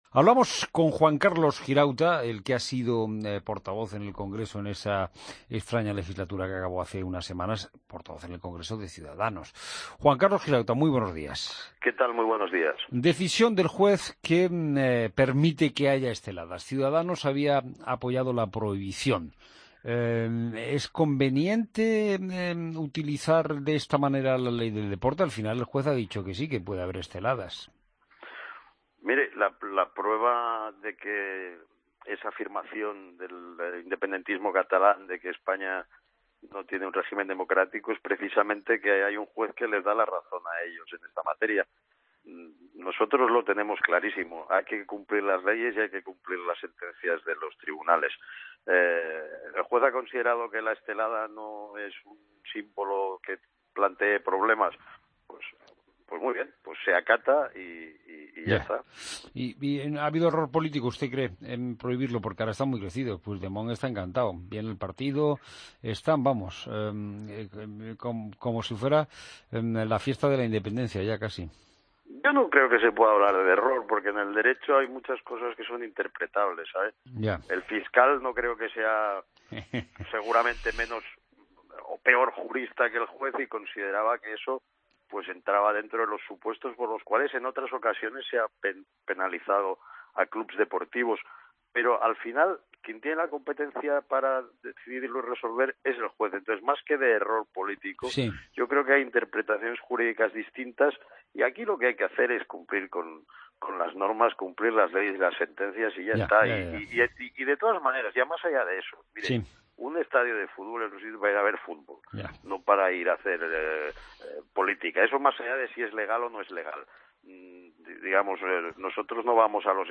Juan Carlos Girauta, portavoz de Ciudadanos en el Congreso de los Diputados, en Fin de Semana